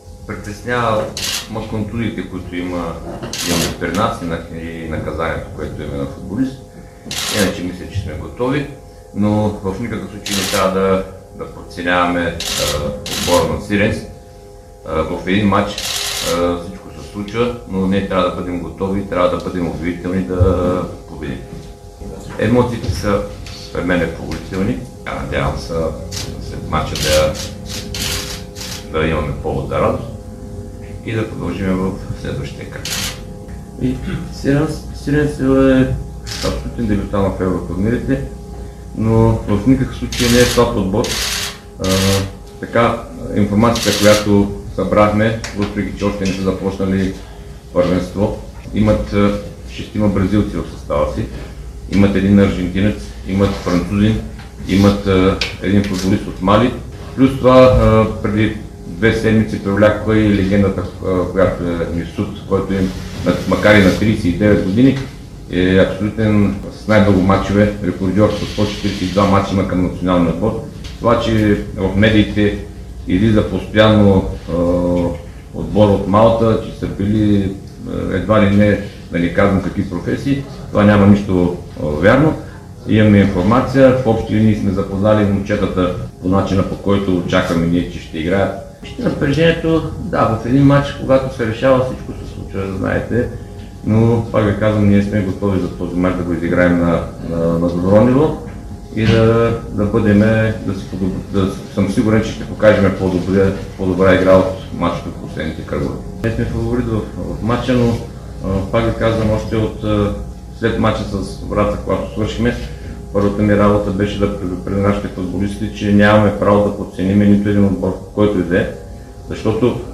говори пред медиите